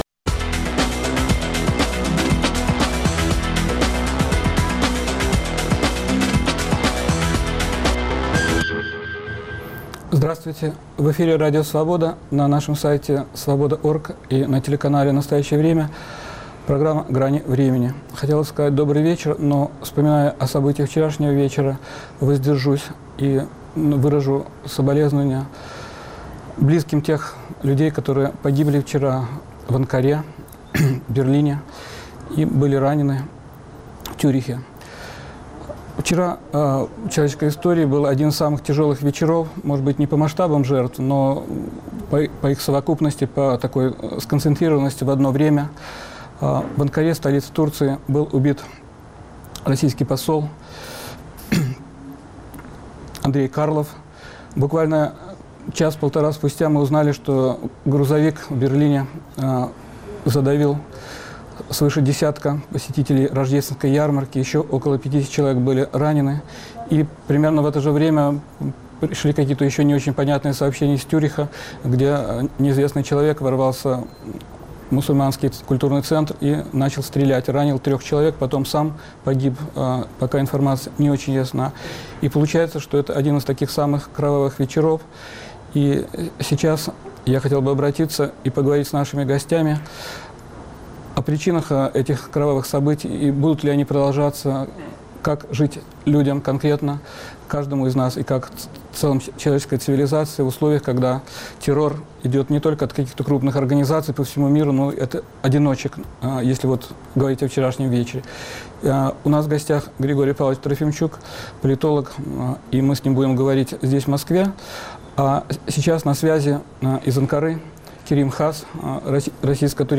Обсуждают политологи